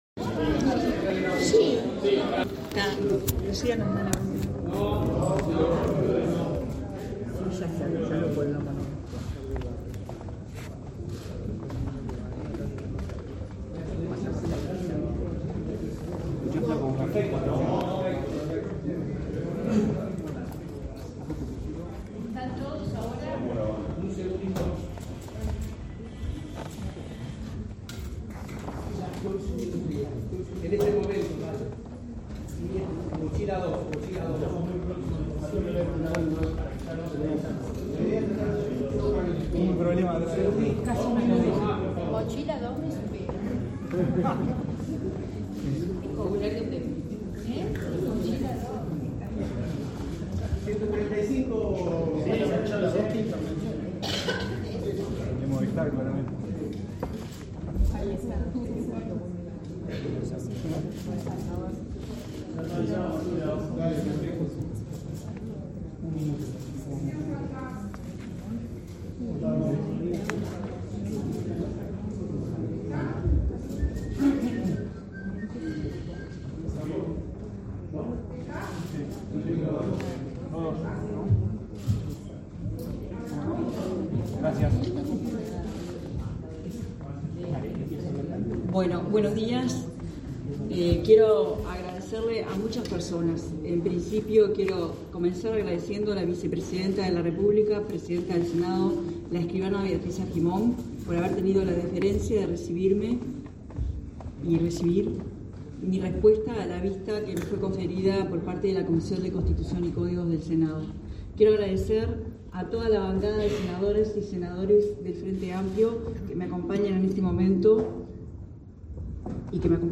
Posteriormente, la intendenta dio una conferencia de prensa rodeada de la bancada de senadoras y senadores frenteamplistas en la cual sostuvo que el juicio político a su persona no tiene «ningún sustento jurídico» y que la Intendencia de Montevideo es la más transparente de todo el país según el ranking de Agesic.
Conferencia_Carolina_Cosse-2.mp3